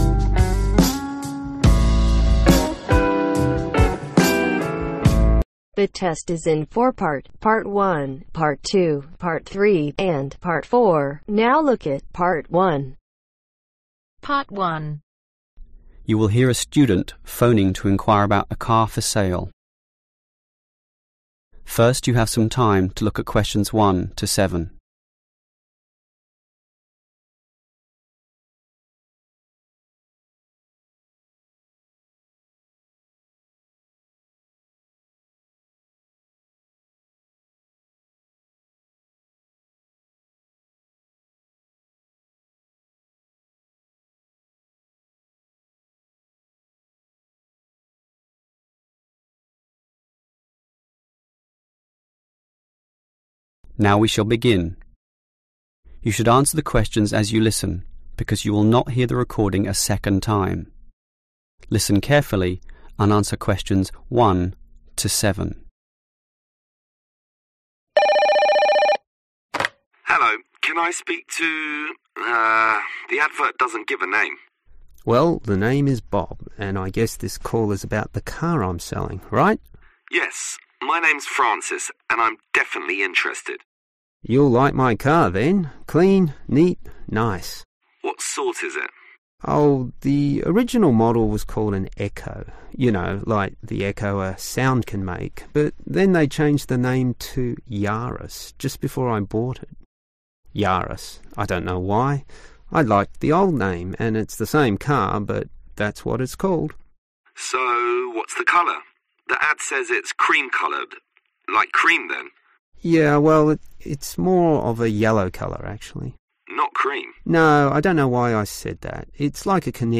Practice of listening test of ielts with answer